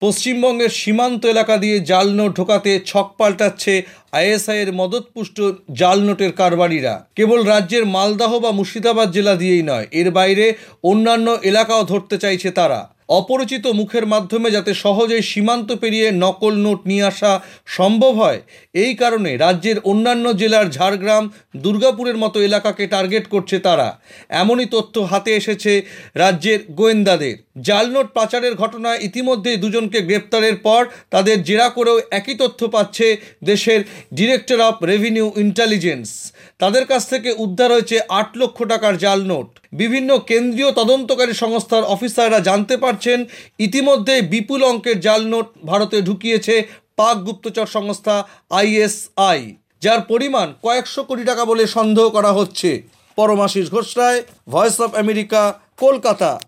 কলকাতা সংবাদদাতা